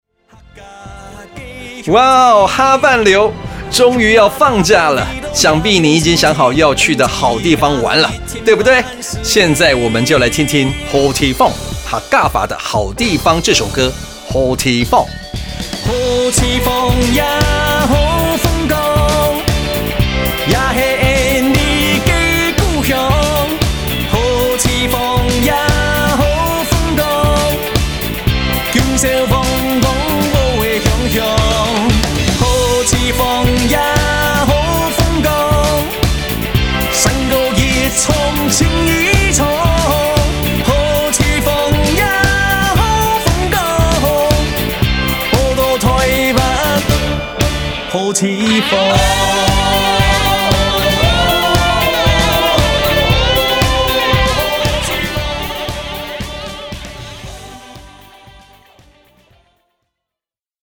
新北客語廣播(海陸)-週末晚安版 | 新北市客家文化典藏資料庫